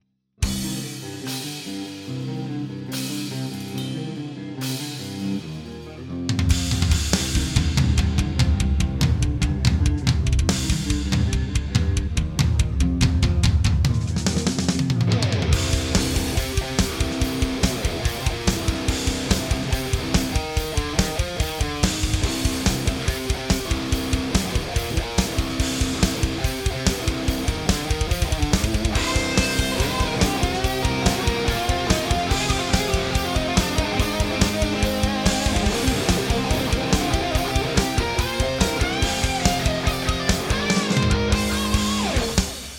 Das ganze jeweils hart links bzw. hart rechts im Panorama, angefettet mit einem kleinen Delay mit 24ms,0FDBK,100MIX um einen gewissen Doppel-Effekt zu erzeugen.
Edit: Hier mal ein 2-Take mit schlechten EZ-Drums dahinter (Gitarre mit 81 am Steg in Drop C) Anhänge Metall Idee 28_1_21.mp3 1.004,7 KB · Aufrufe: 557